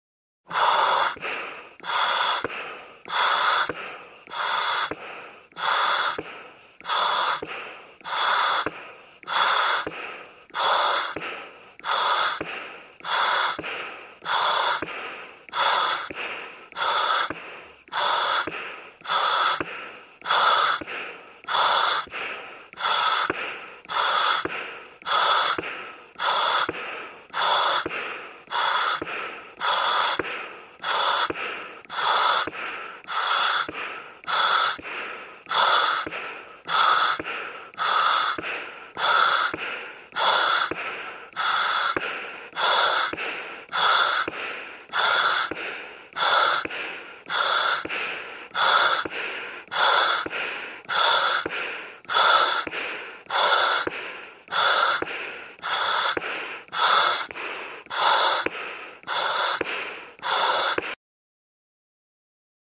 Звуки противогаза
Звук быстрого дыхания человека в противогазе